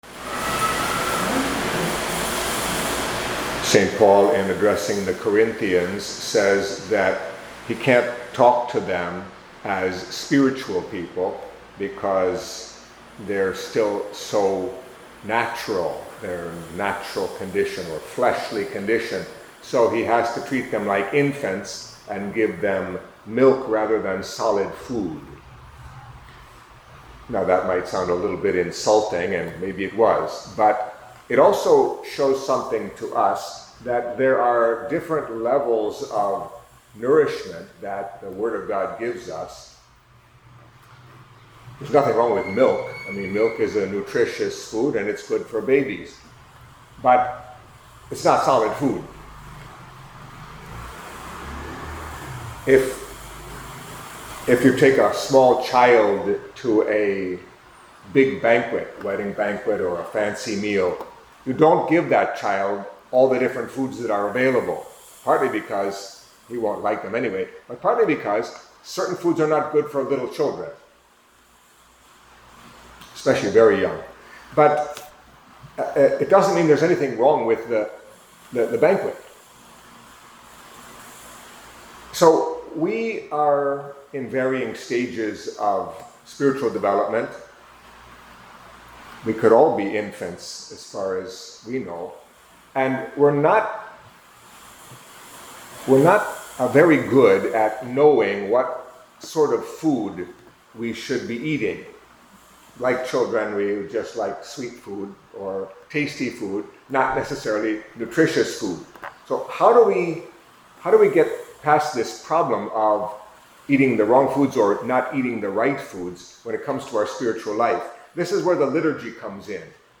Catholic Mass homily for Wednesday of the Twenty-Second Week of Ordinary Time